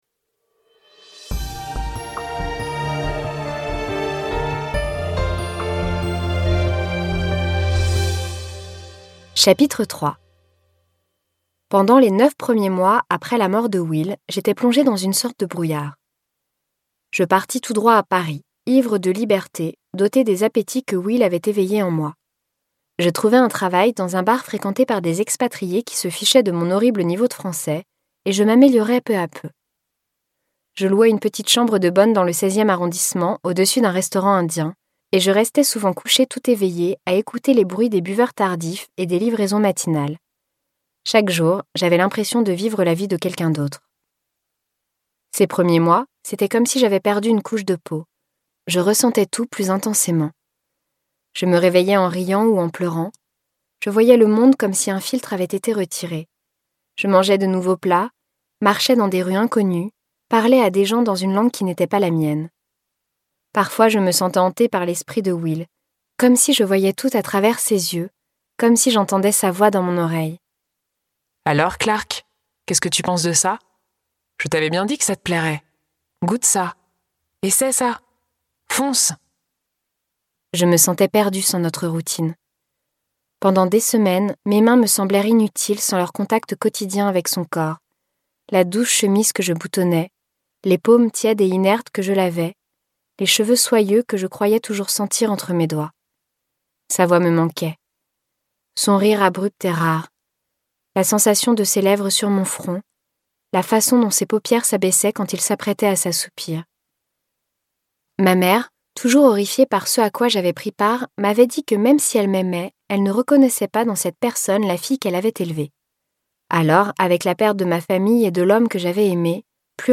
Extrait gratuit